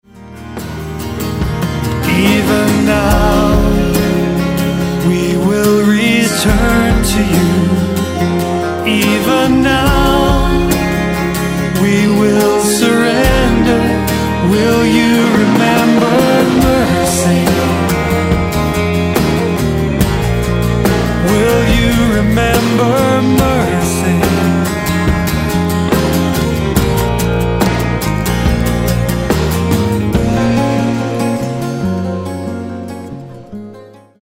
Inspiring lyrics and quality instrumentation.